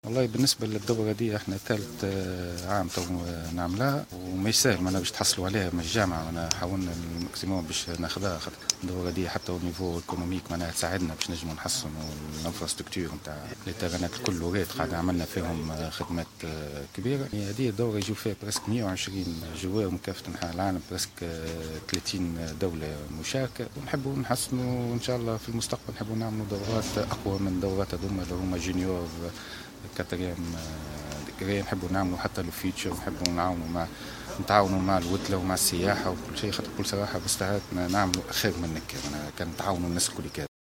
انعقدت صباح اليوم بمقر نادي التنس بالمهدية ندوة صحفية تحت اشراف الجامعة الدولية والجامعة التونسية للتنس ونادي التنس بالمهدية للحديث حول تنظيم الدورة الدولية للتنس للأواسط .